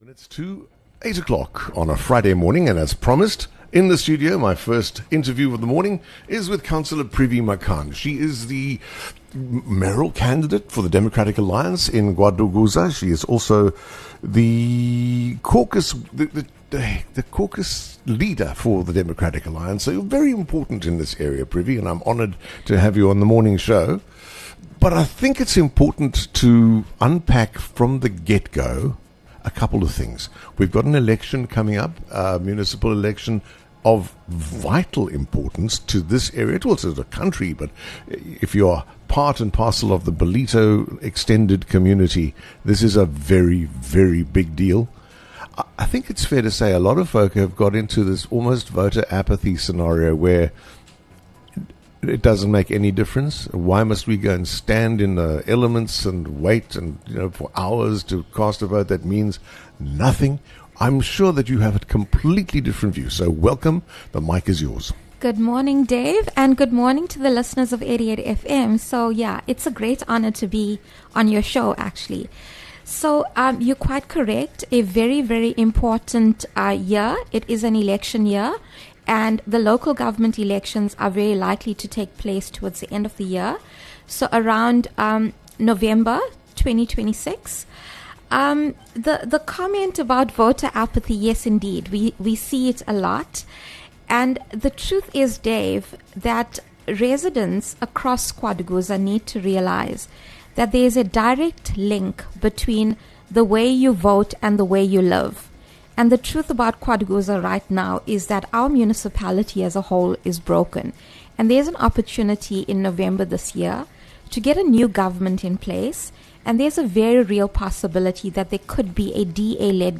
20 Mar DA KwaDukuza Mayoral Candidate, Councillor Privi Makhan discuss the DAs plan to get KwaDukuza working